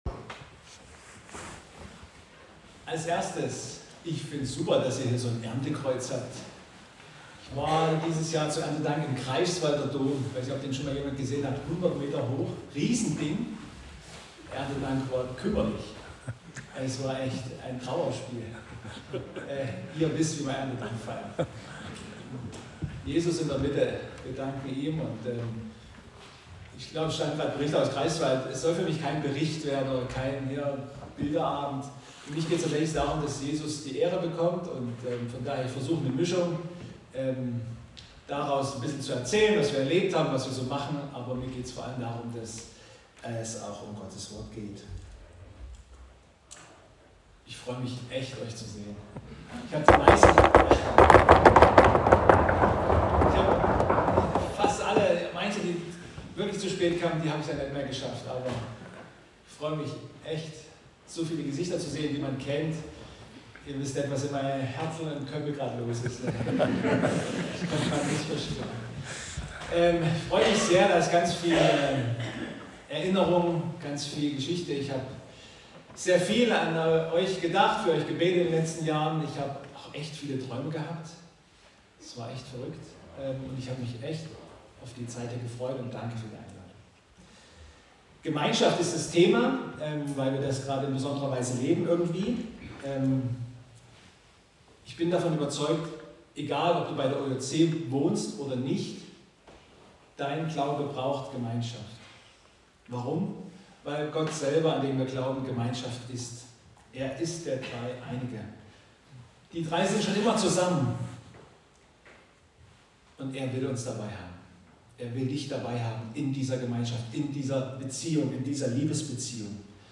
Gottesdienstart: Missionswerk